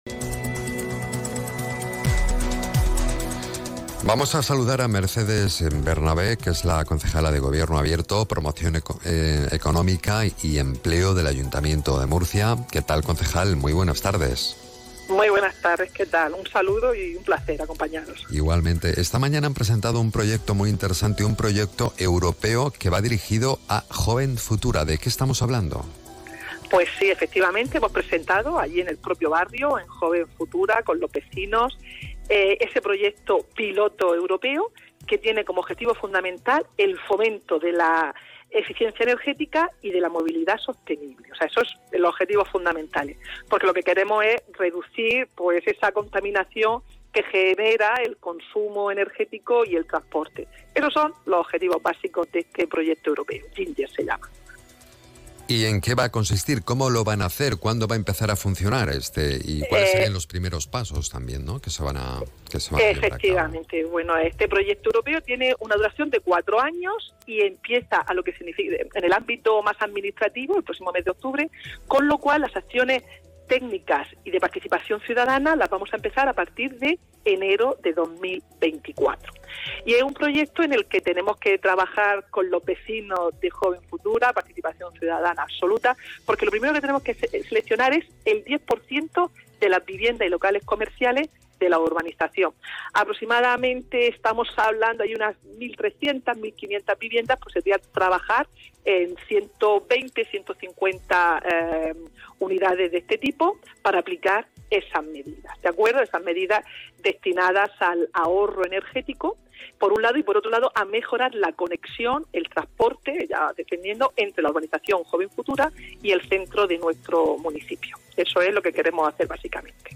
Extracto directo al audio de la noticia: